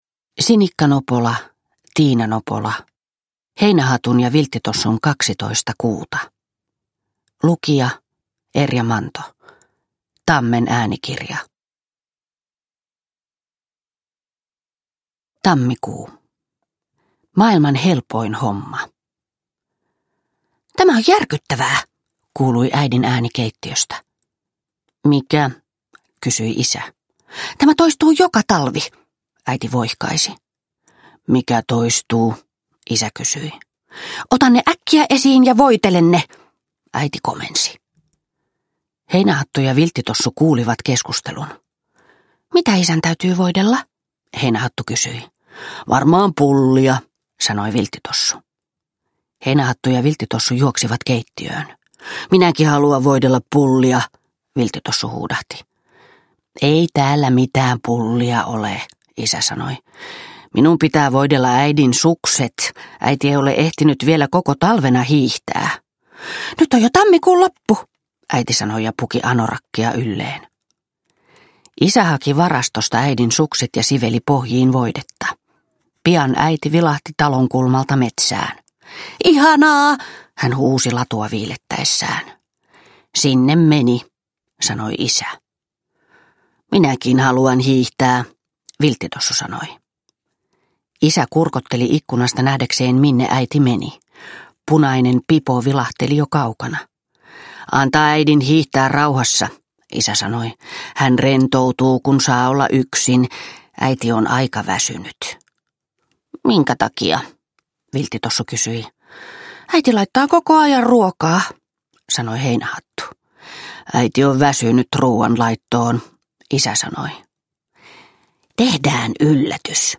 Heinähatun ja Vilttitossun kaksitoista kuuta – Ljudbok
• Ljudbok